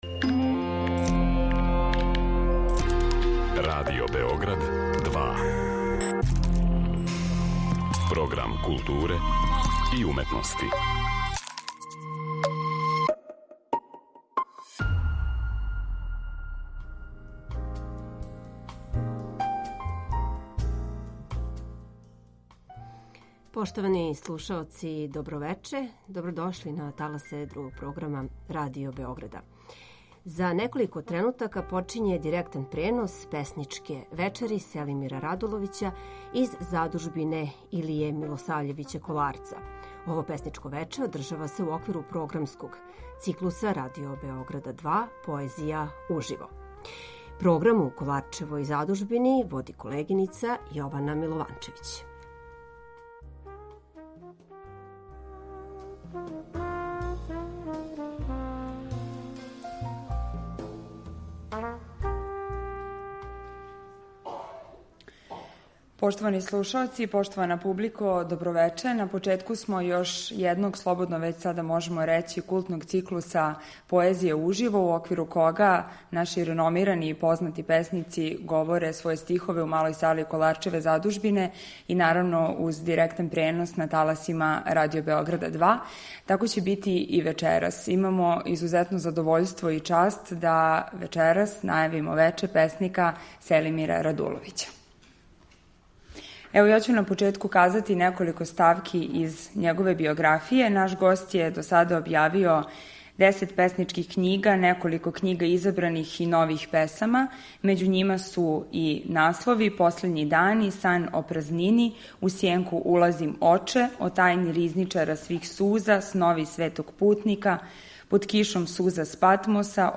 у Малој сали Коларчеве задужбине
уз директан пренос на таласима Радио Београда 2.